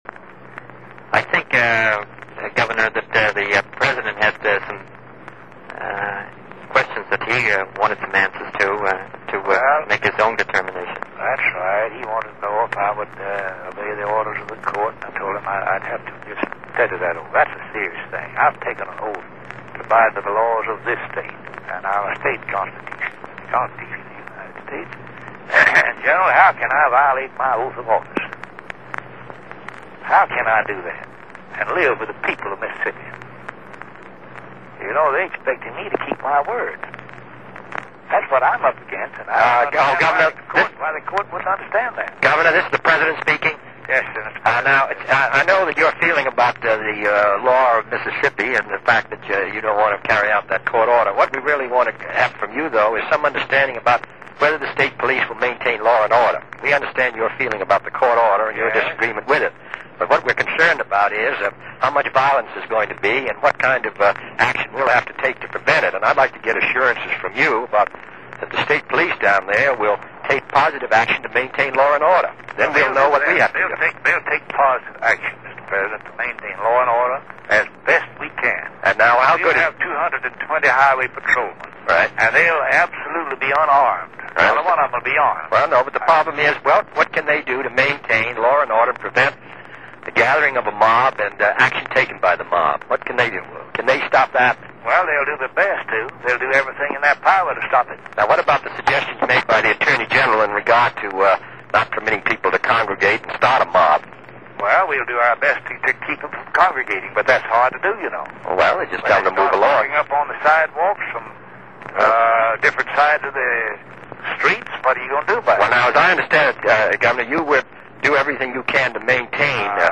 President Kennedy, Attorney General Kennedy, and Mississippi governor Ross Barnett, 29 Sept. 1962, re: James Meredith and Ole Miss